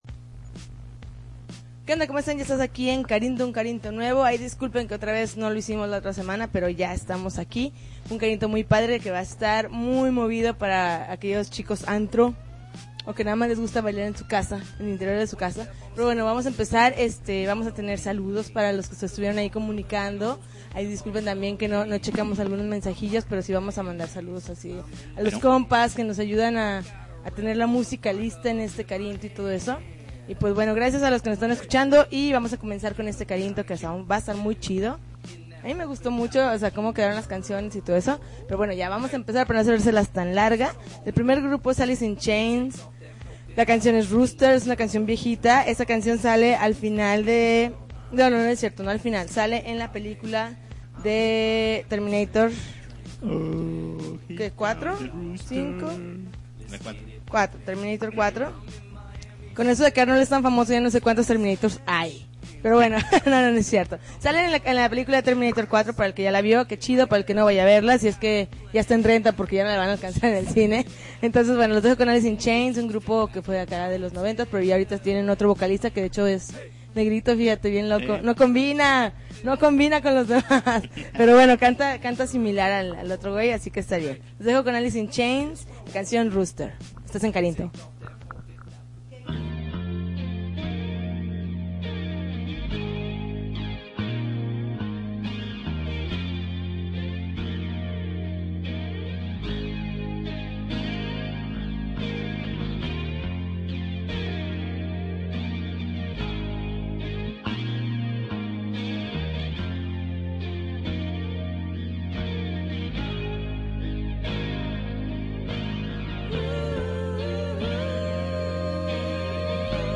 August 30, 2009Podcast, Punk Rock Alternativo